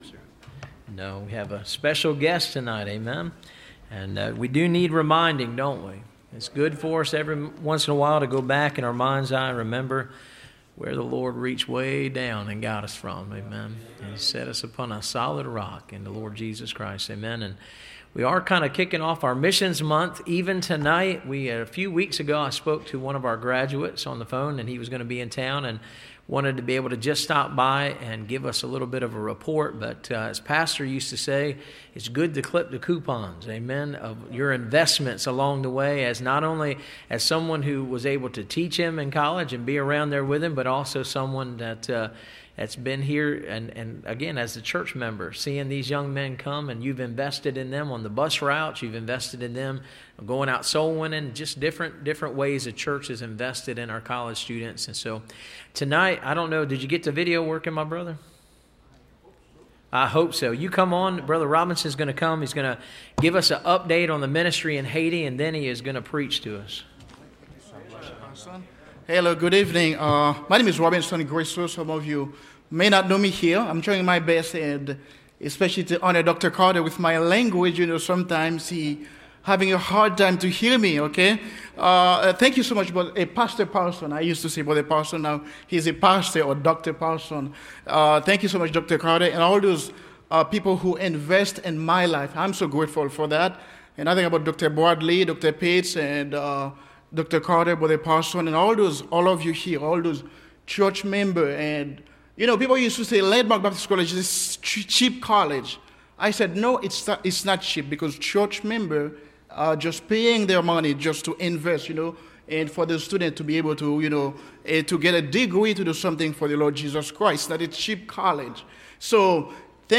Haiti Missionary Report – Landmark Baptist Church
Service Type: Wednesday